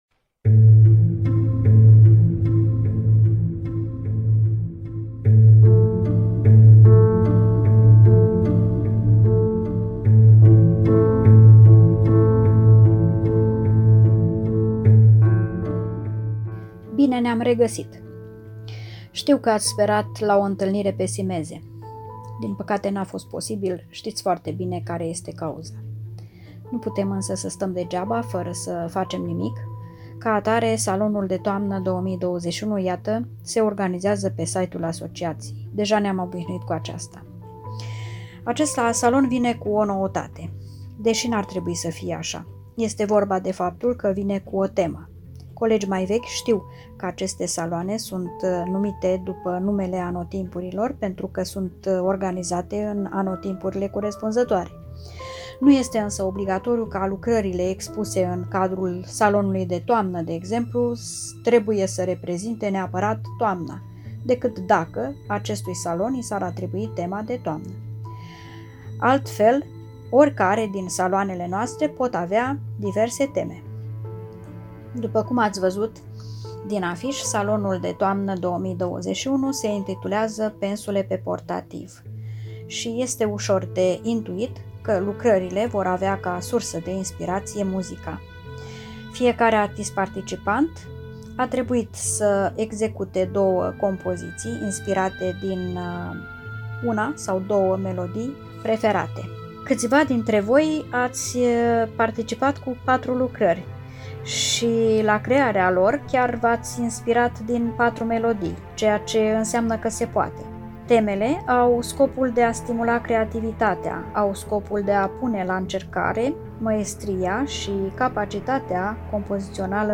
float: left; PREZENTAREA si PREMIERILE